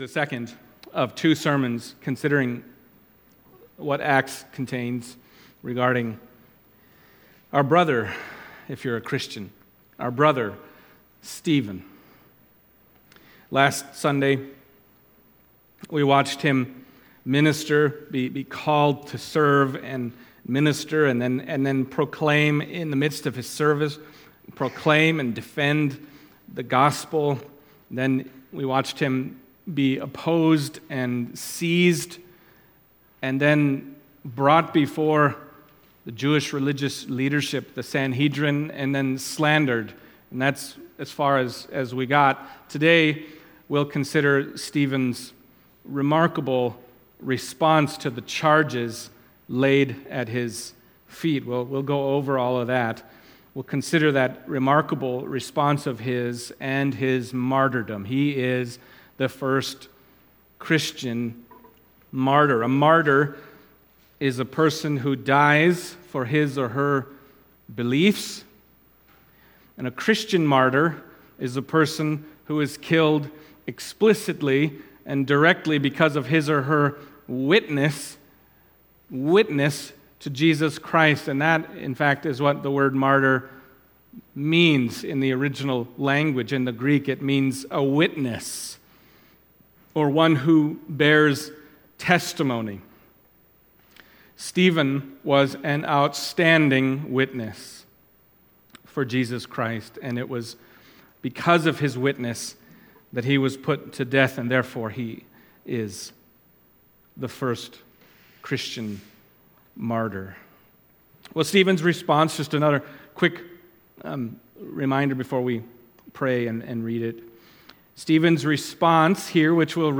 Acts 7:1-8:3 Service Type: Sunday Morning Acts 7:1-8:3 « Stephen